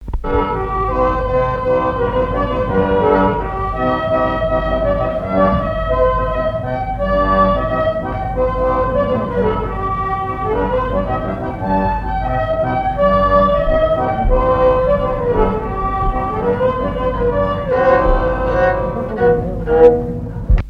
Mémoires et Patrimoines vivants - RaddO est une base de données d'archives iconographiques et sonores.
Chants brefs - A danser
danse : scottich sept pas
Assises du Folklore
Pièce musicale inédite